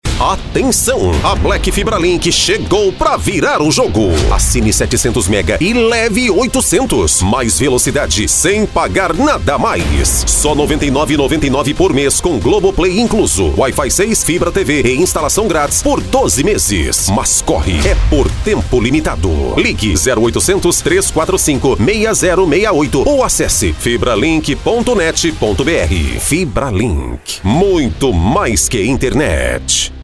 Estilo Black Friday: ritmo acelerado, impacto e emoção.
Subir o tom nas palavras “CHEGOU”, “BLACK FIBRALINK” e “Ligue agora!”.
Dar pausas curtas e marcadas após cada benefício.
Fechar com voz mais grave e lenta, reforçando a força da marca.